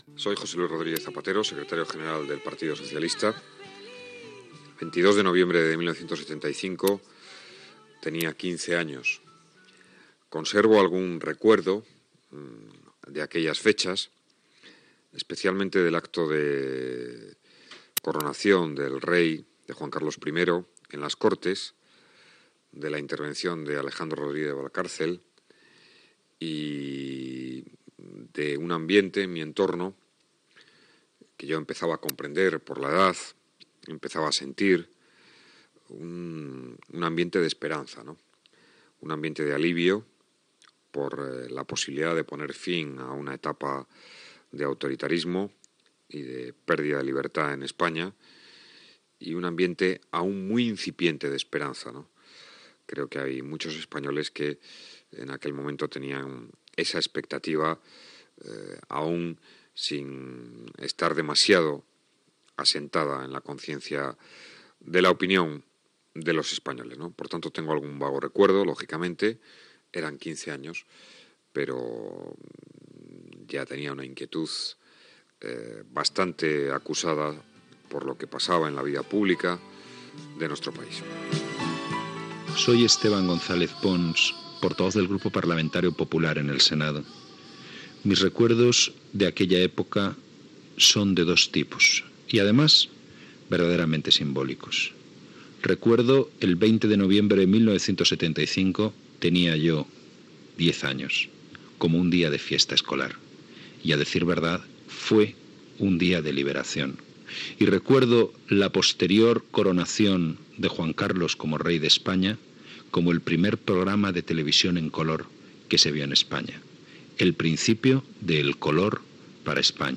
Records dels parlamentaris José Luis Rodríguez Zapatero (PSOE), Esteban González Pons (Partido Popular) i José Antonio Labordeta (Chunta Aragonesista) de la data de coronació de Juan Carlos de Borbón com a rei d'Espanya el 22 de novembre de 1975
Informatiu